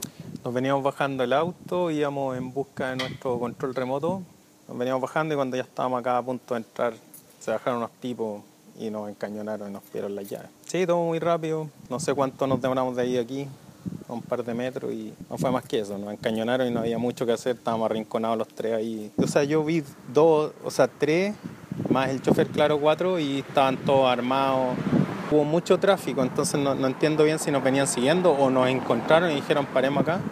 Una de las víctimas relató cómo ocurrieron los hechos y destacó la violencia con la que fueron bajados del vehículo.